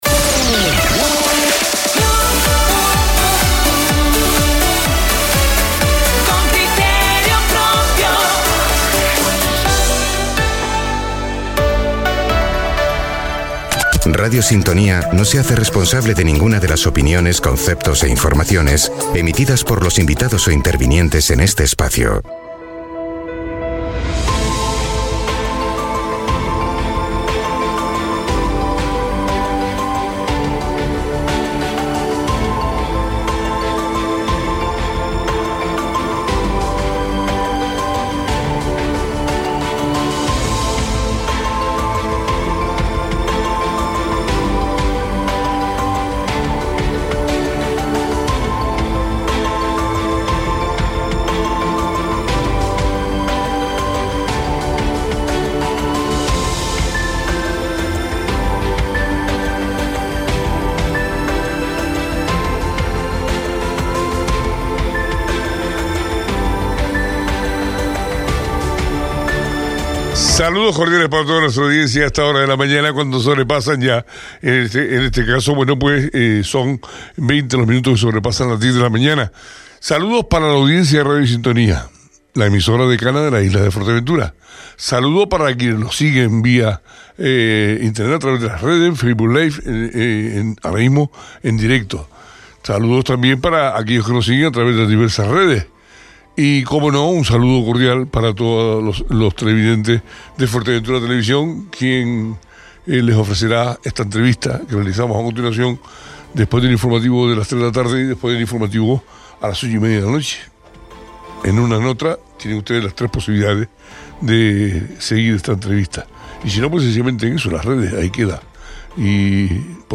Blas Acosta, vicepresidente y consejero de Infraestructuras del Cabildo de Fuerteventura visita el estudio. - Radio Sintonía
Blas Acosta, vicepresidente y consejero de Infraestructuras del Cabildo de Fuerteventura visita el estudio de la radio esta mañana.
Entrevistas Blas Acosta, vicepresidente y consejero de Infraestructuras del Cabildo de Fuerteventura visita el estudio.